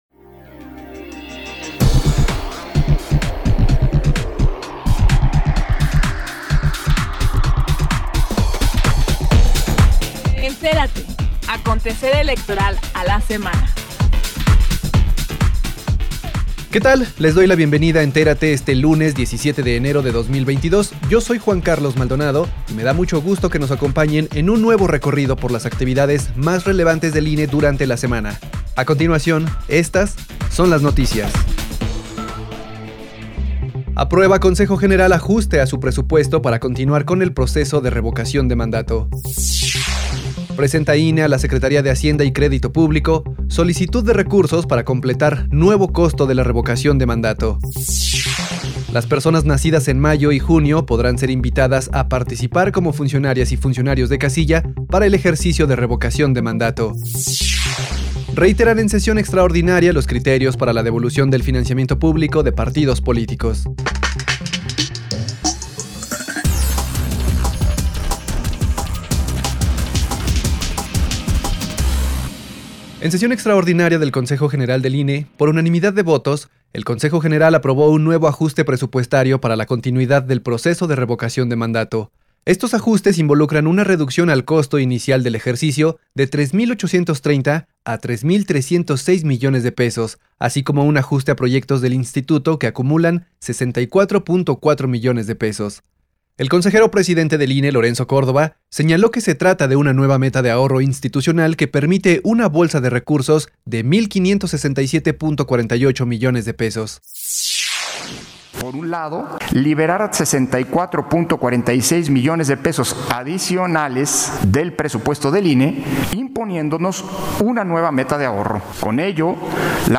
NOTICIARIO-17-ENERO-2022